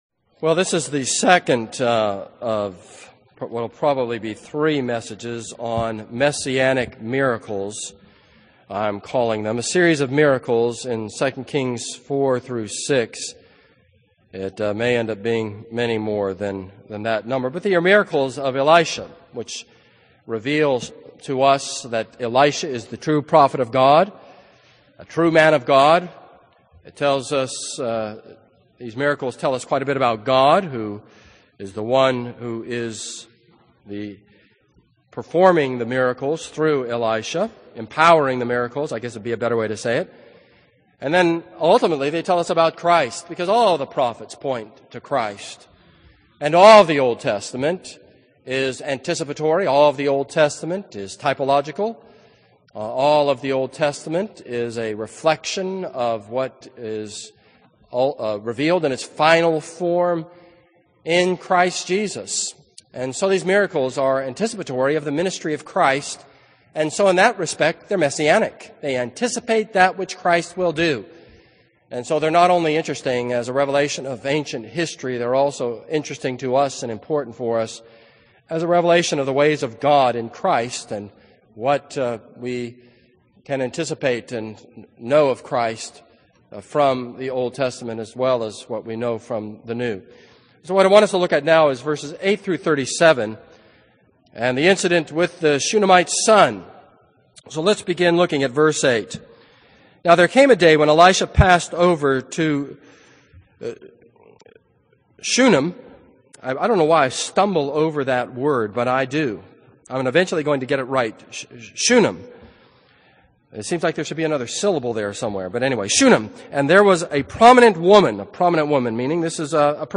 This is a sermon on 2 Kings 4:8-37.